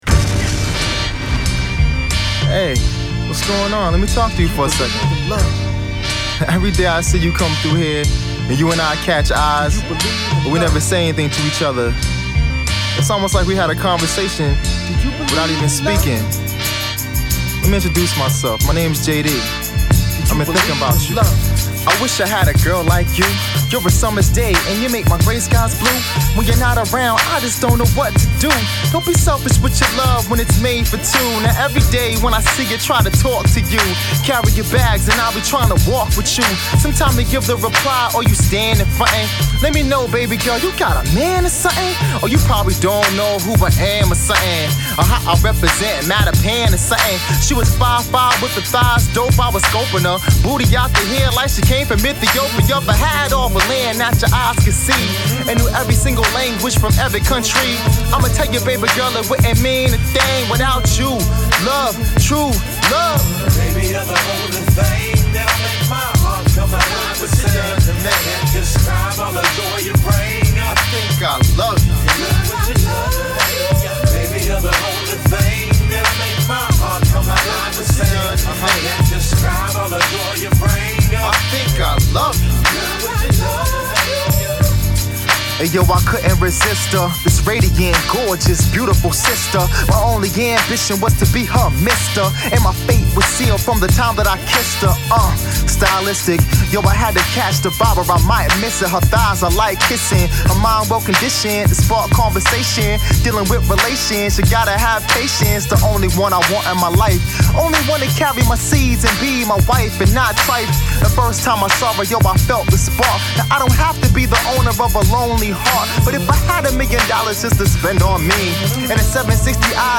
mixtape
A 3 piece group from Boston City